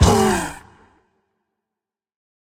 mob / warden / hurt_4.ogg
hurt_4.ogg